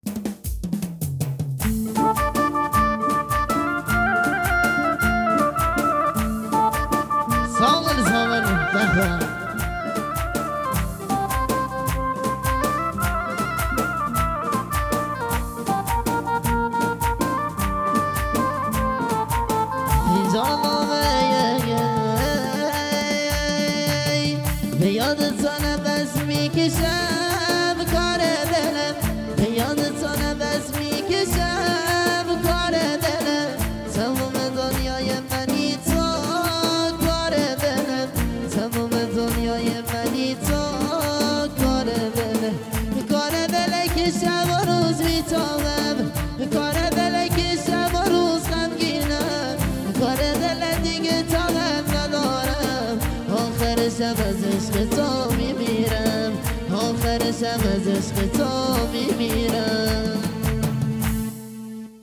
کیفیت بالا
ترانه محلی زیبا و دلنشین